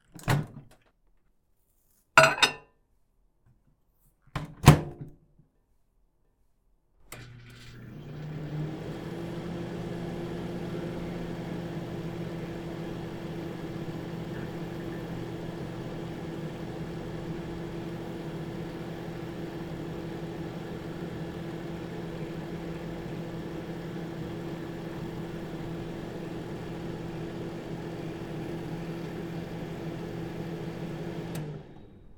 料理・台所の音
電子レンジ温め一連